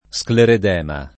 vai all'elenco alfabetico delle voci ingrandisci il carattere 100% rimpicciolisci il carattere stampa invia tramite posta elettronica codividi su Facebook scleredema [ S klered $ ma ] o scleroedema s. m. (med.); pl.